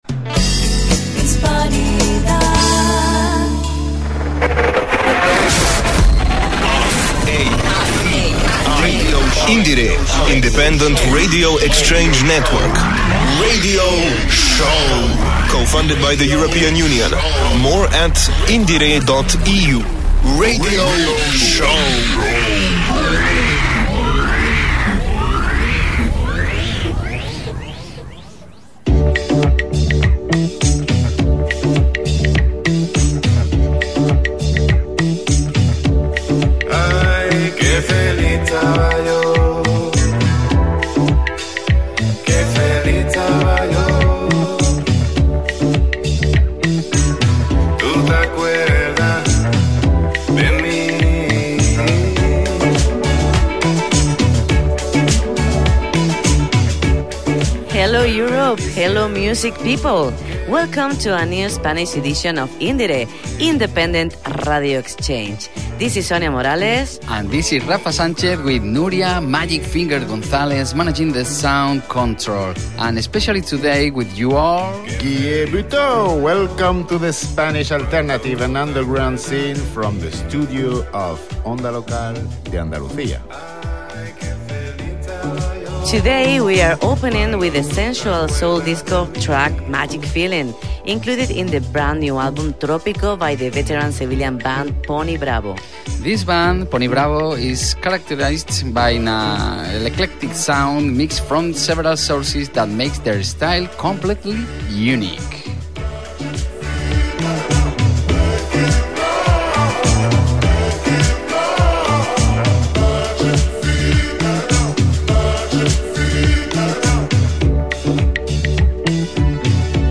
BROADCASTS TITLE & NUMBER OF THE BROADCAST: #120-indr2.0-br-emartvsevilla DESCRIPTION OF THE CONTENT: Punk, garage and rap come together at the 120th edition of IndieRE.